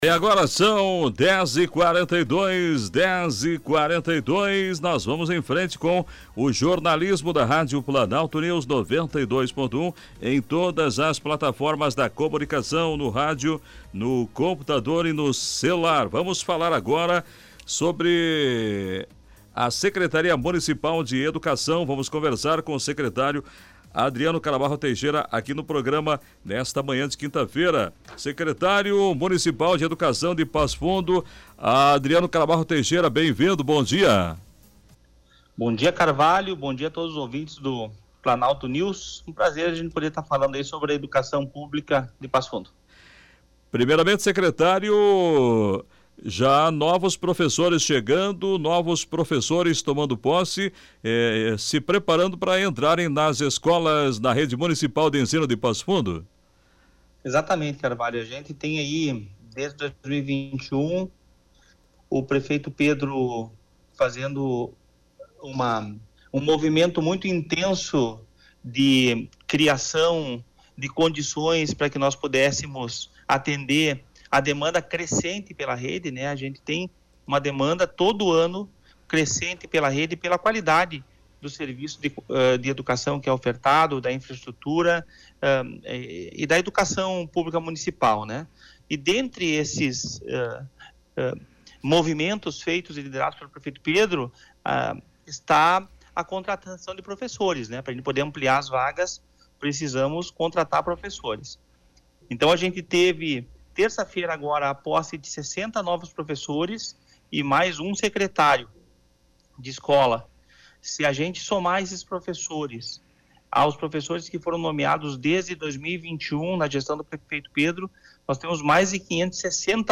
Entrevista com secretário Adriano Teixeira: preparativos finais para volta às aulas em Passo Fundo0